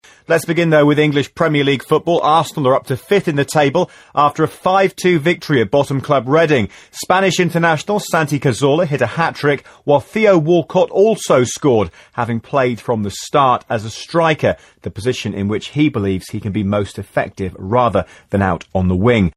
【英音模仿秀】卡索拉帽子戏法 听力文件下载—在线英语听力室